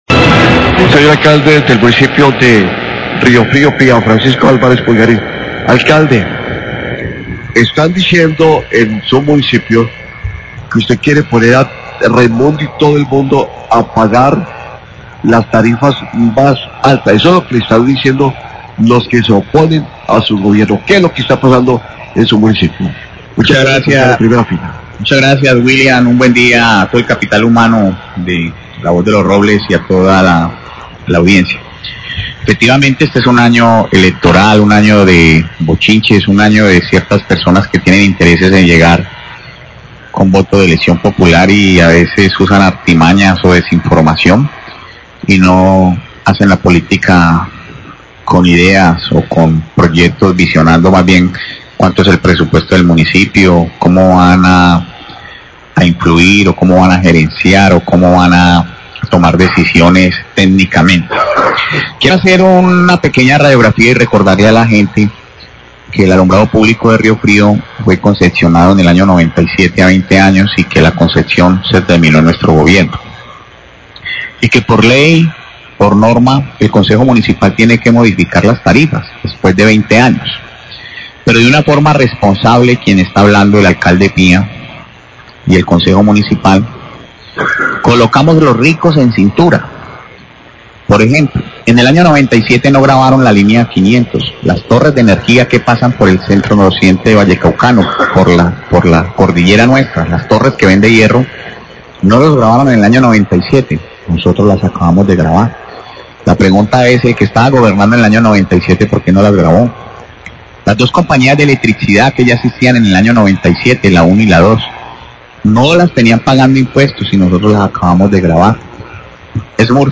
HABLA EL ALCALDE DE RIOFRÍO SOBRE EL COBRO DEL ALUMBRADO PÚBLICO EN EL MUNICIPIO, ROBLES, 751am